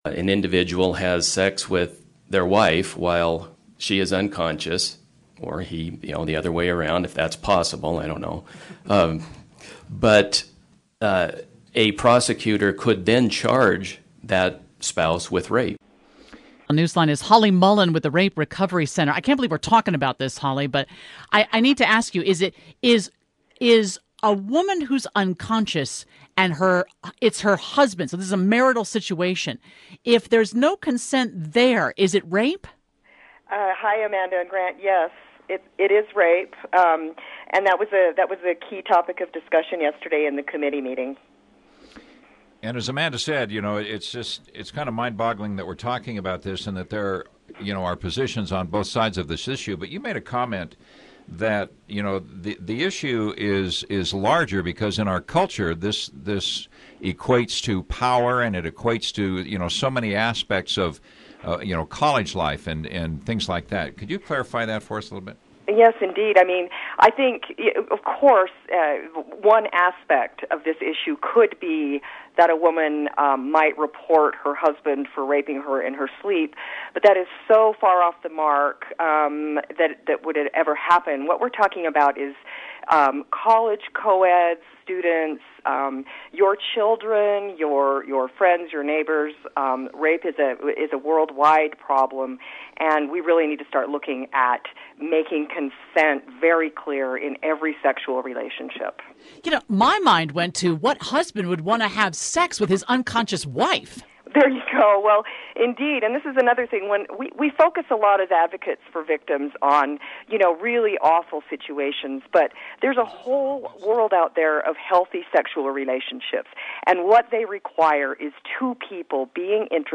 The House Judiciary Committee had what one lawmaker called an "uncomfortable discussion" about HB 74. This sound starts with Representative Brian Greene